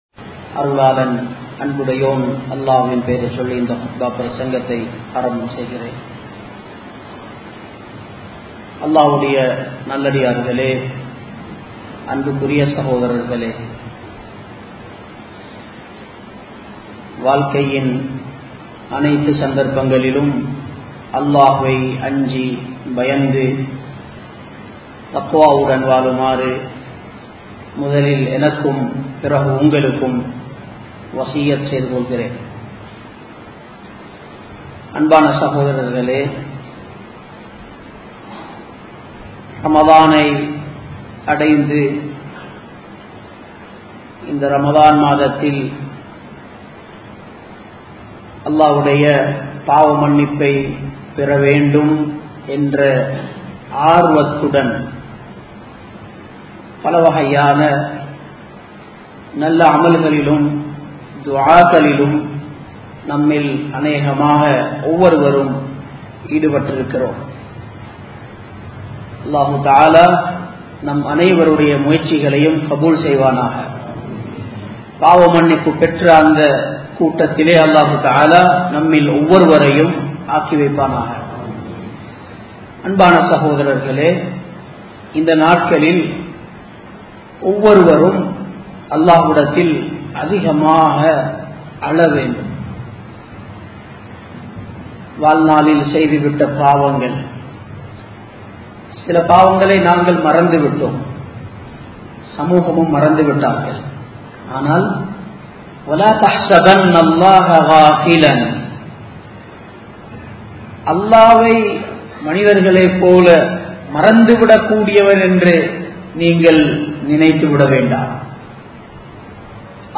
Thawba Entraal Enna? (தௌபா என்றால் என்ன?) | Audio Bayans | All Ceylon Muslim Youth Community | Addalaichenai
Colombo 11, Samman Kottu Jumua Masjith (Red Masjith)